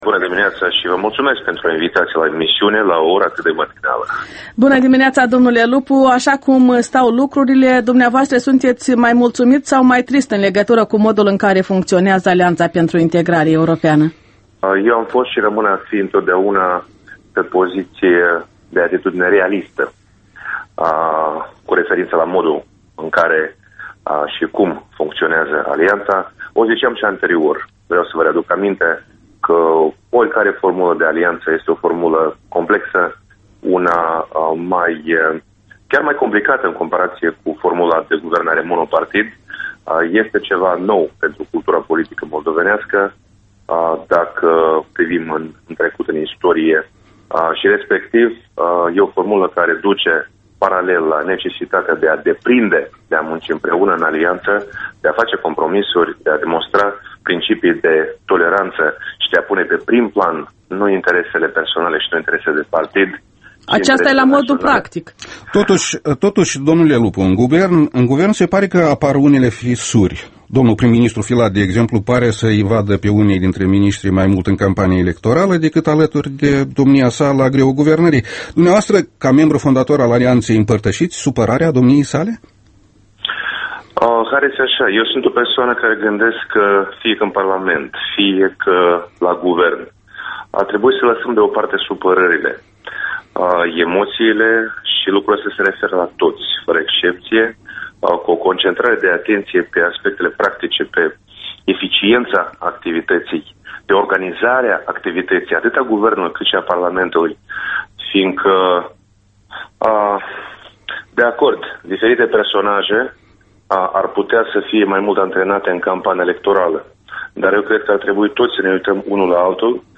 Interviul matinal al EL: cu Marian Lupu, președintele PD